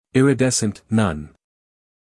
英音/ ˌɪrɪˈdes(ə)nt / 美音/ ˌɪrɪˈdes(ə)nt /
wordmp3iridescent_mp3.mp3